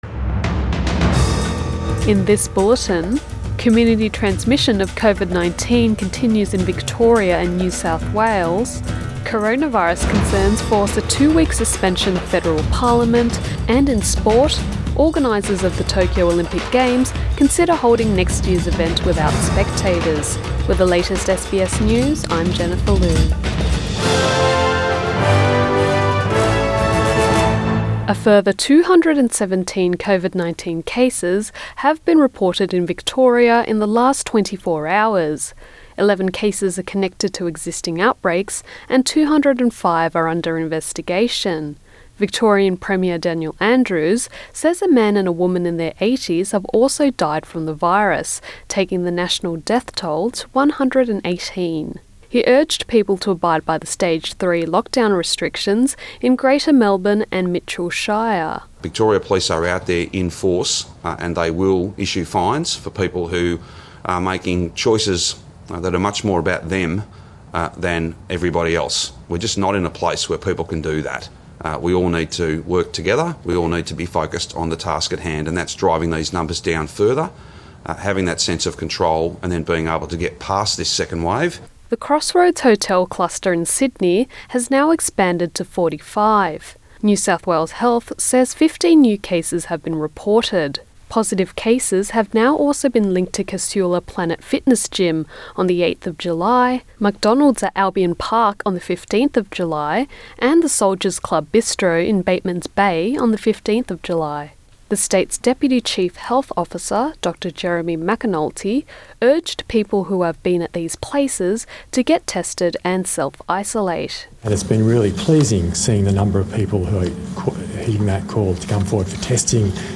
PM bulletin 18 July 2020